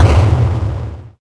clear_3.ogg